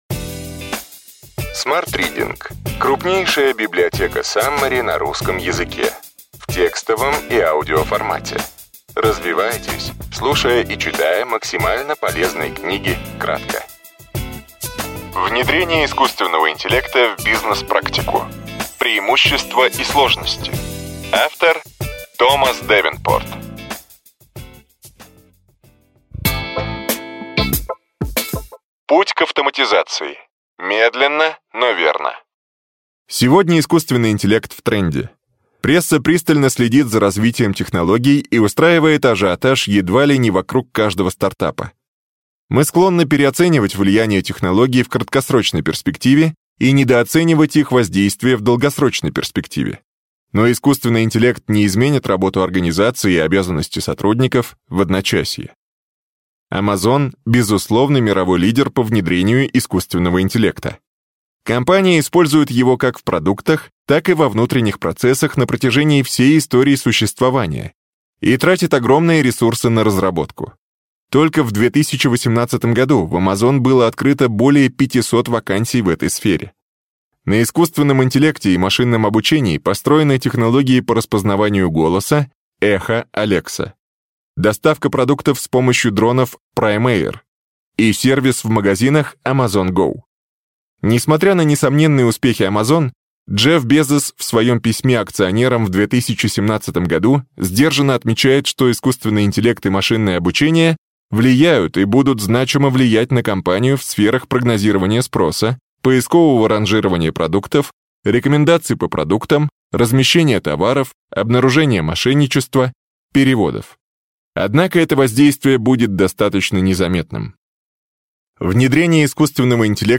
Аудиокнига Ключевые идеи книги: Внедрение искусственного интеллекта в бизнес-практику.